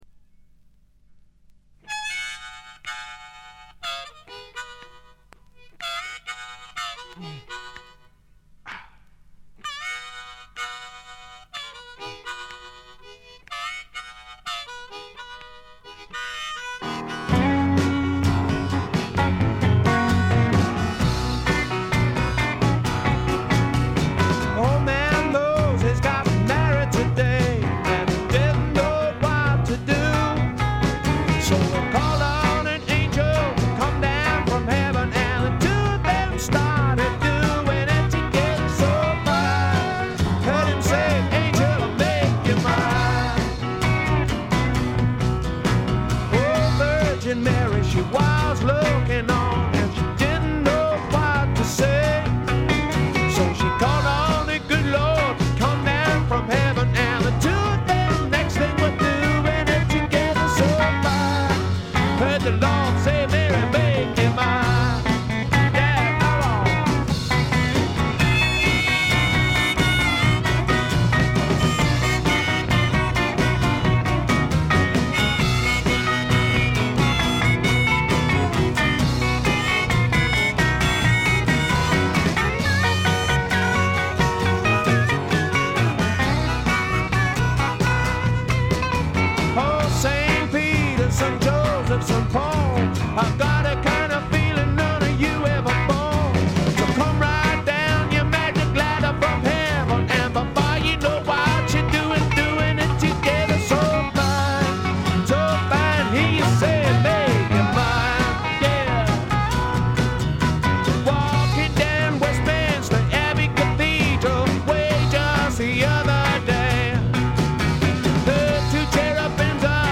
ほとんどノイズ感無し。
というわけでスモーキーなヴォーカルが冴える霧の英国シンガー・ソングライターの金字塔作品です。
試聴曲は現品からの取り込み音源です。